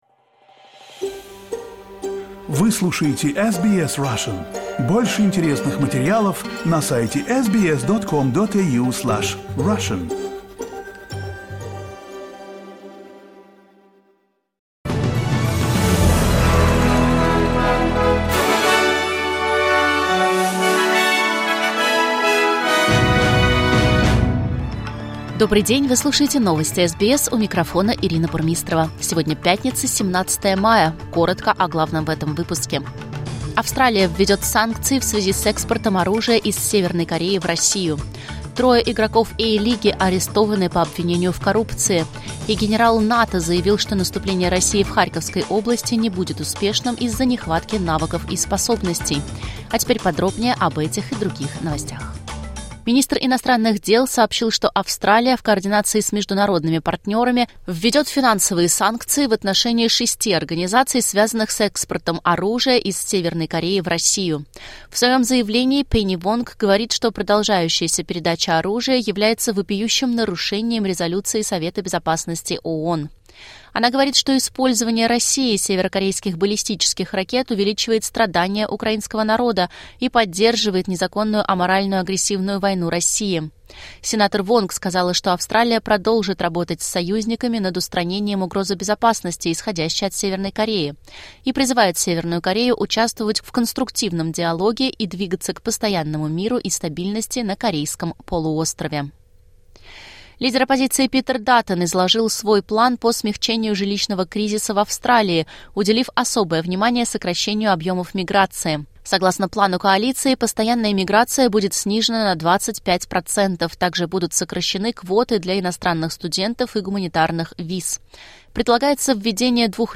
Latest news headlines in Australia from SBS Russian